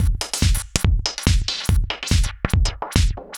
Index of /musicradar/uk-garage-samples/142bpm Lines n Loops/Beats
GA_BeatAFilter142-04.wav